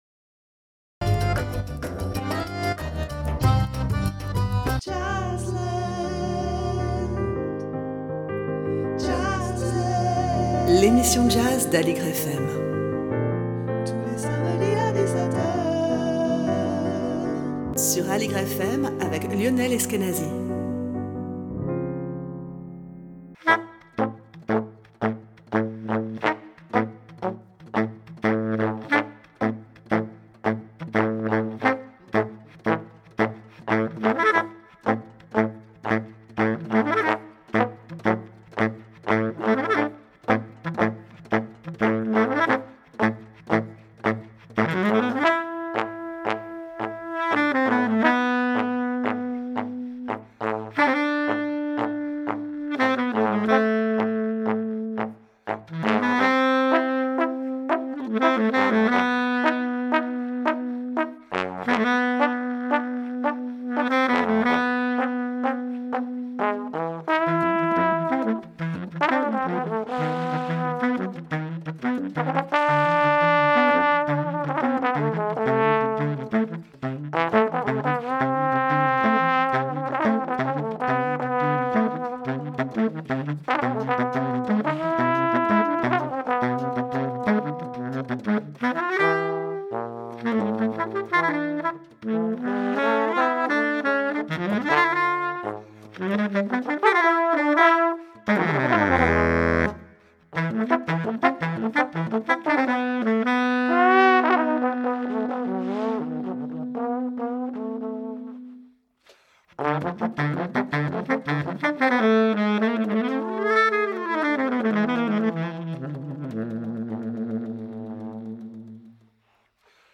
Emission sur l'actualité du jazz
avec comme invité : le tromboniste